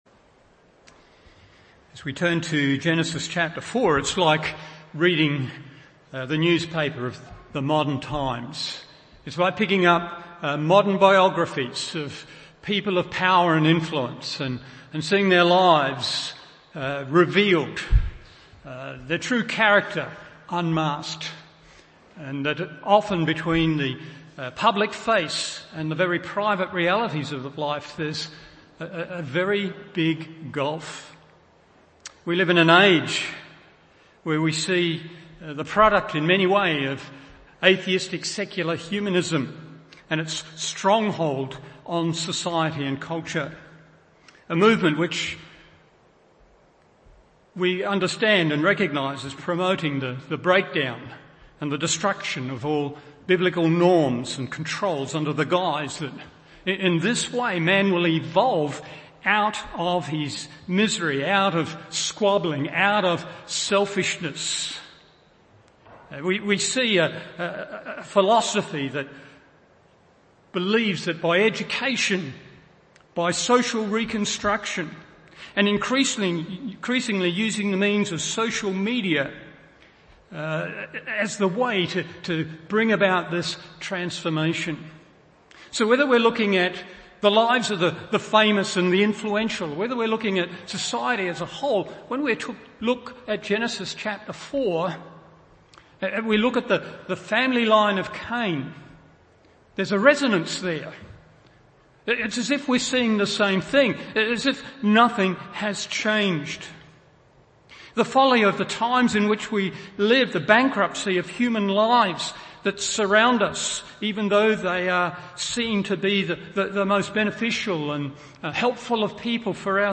Morning Service Genesis 4:16-24 1. Deterioration 2. Development 3.